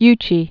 (ychē)